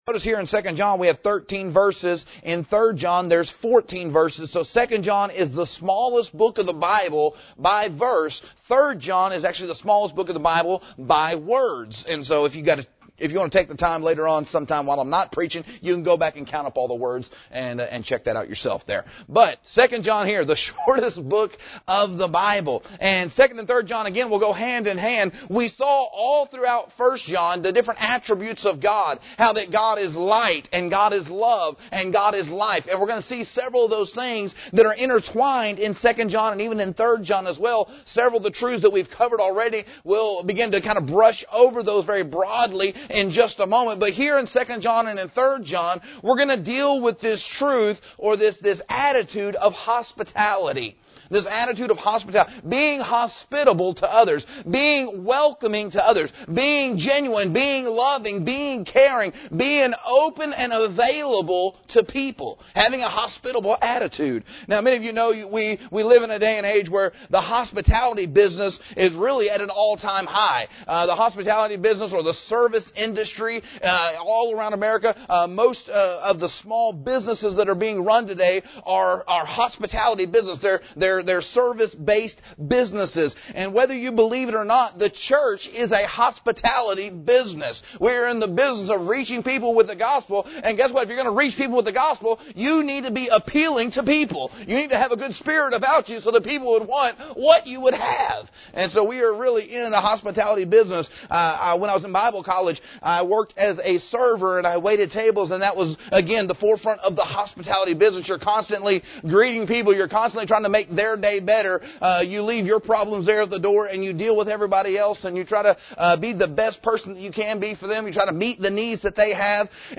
Hospitality – Part 1 – Cornerstone Baptist Church | McAlester, OK